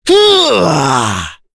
Kain-Vox_Casting3.wav